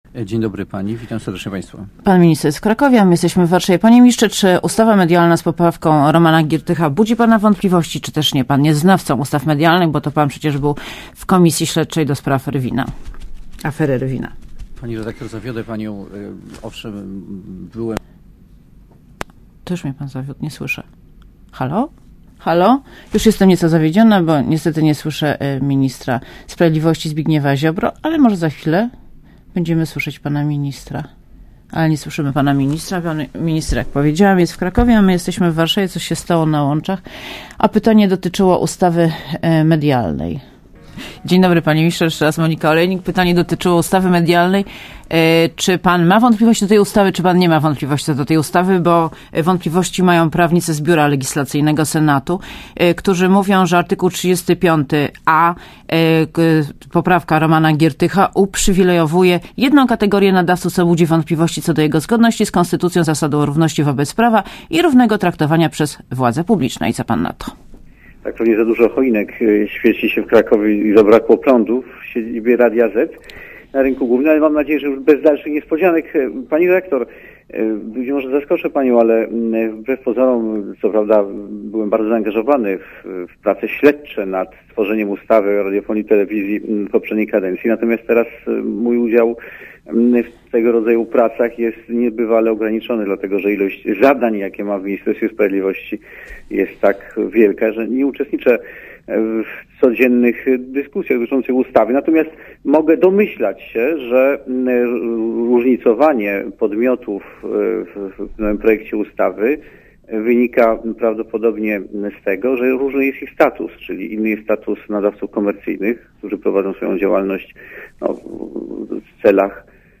Posłuchaj wywiadu Gościem Radia ZET jest Zbigniew Ziobro , minister sprawiedliwości.